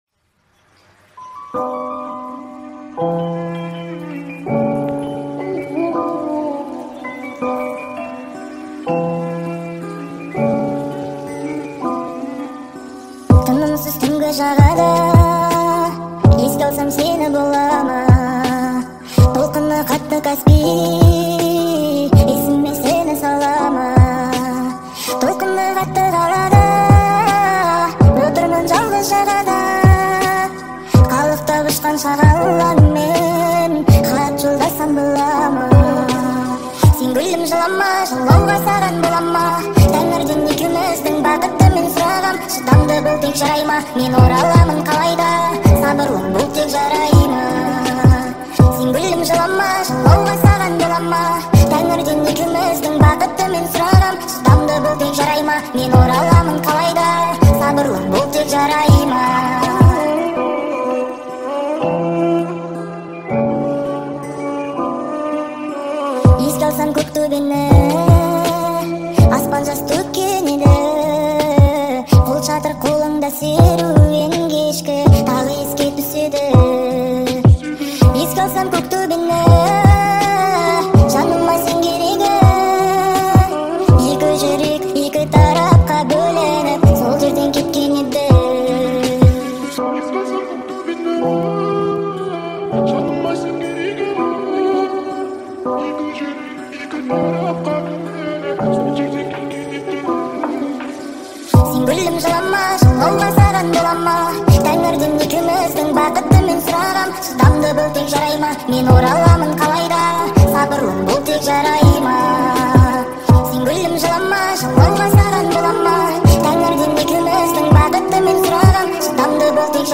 TikTok remix